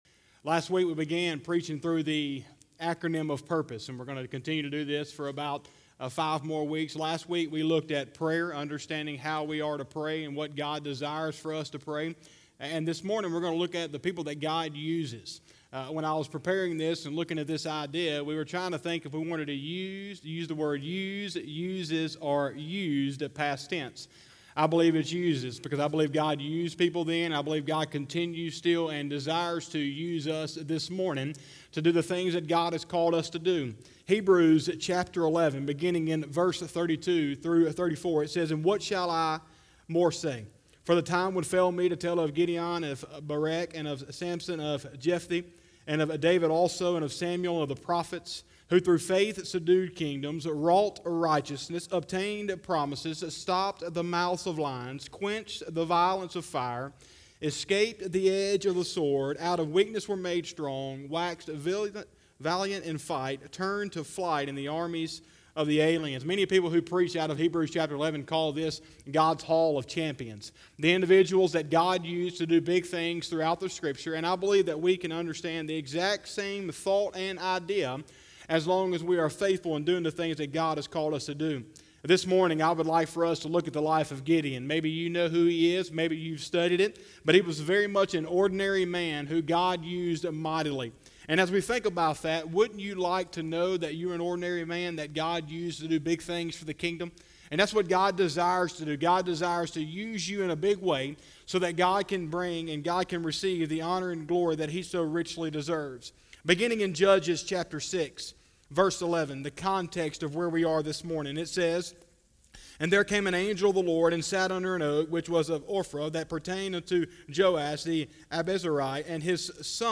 09/13/2020 – Sunday Morning Service